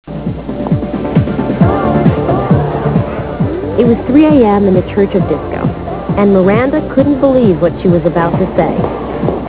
Comment: disco